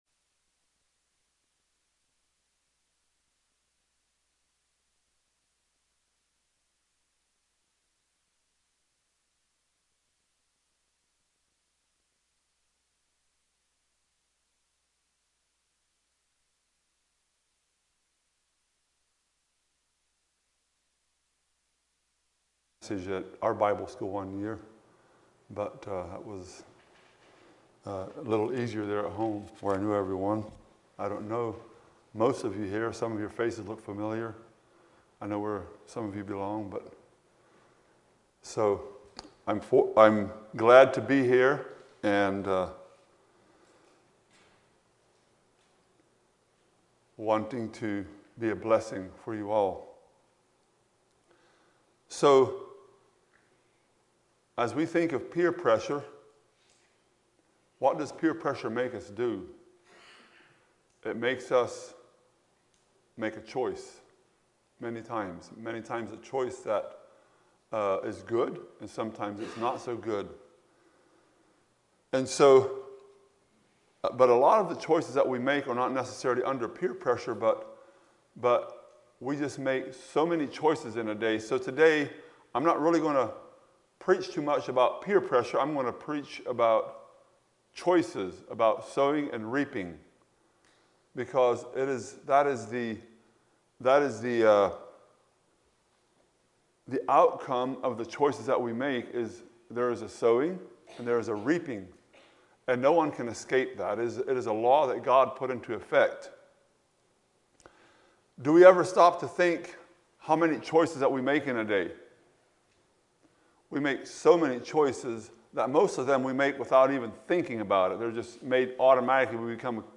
Youth Meeting Messages